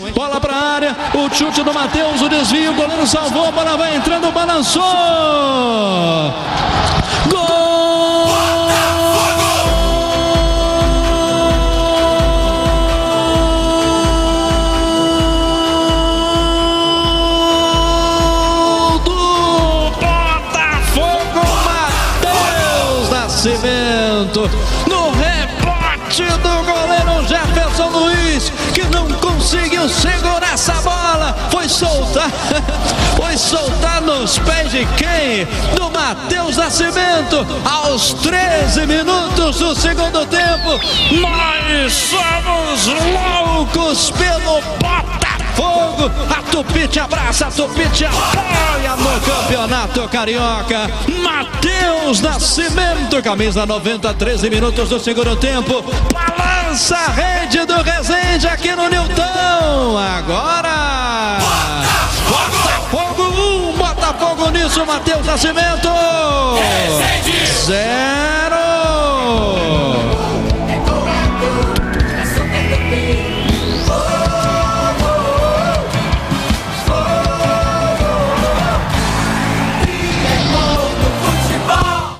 narração
GOL-BOTA-1-X-0-RESENDE-online-audio-converter.com_.mp3